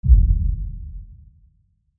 BASS_BOOM_001
bass boom metal sound effect free sound royalty free Music